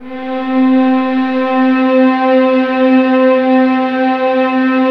VIOLINS DN4.wav